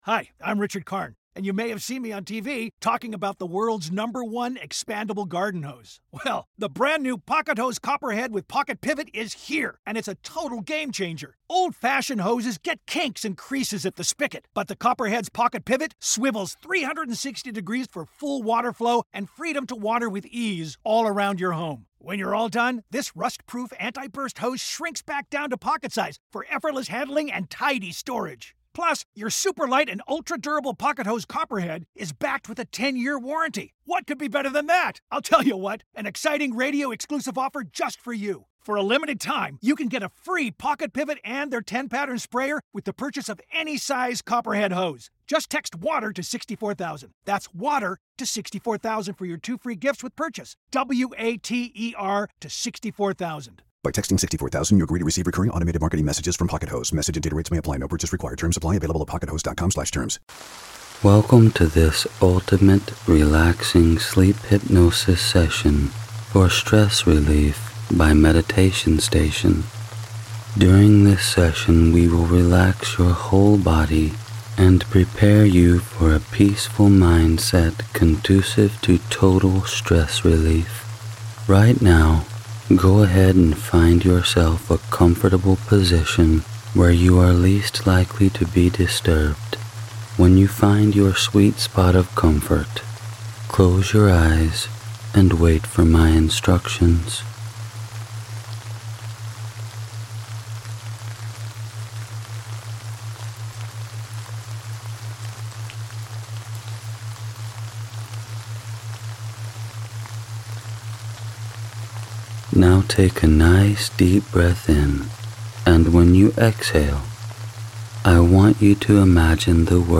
Relax deeply and learn to let go with rain and & relaxing binaural meditation